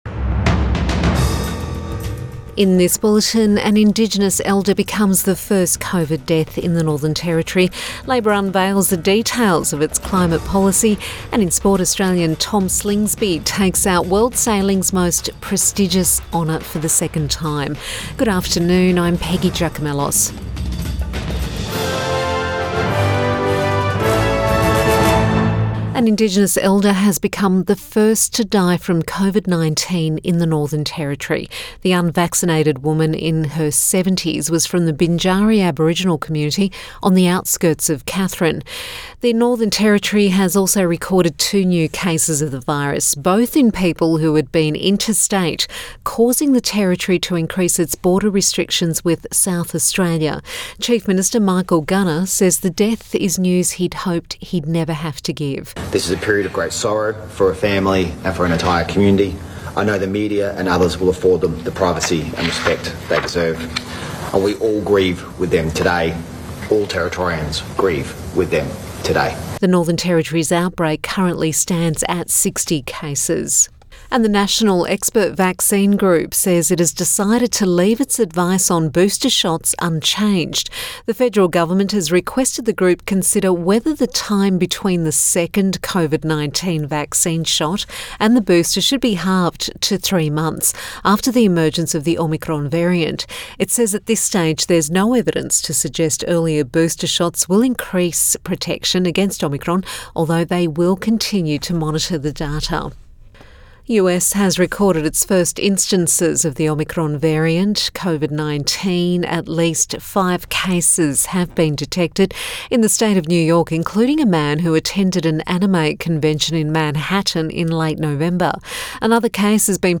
PM bulletin December 3 2021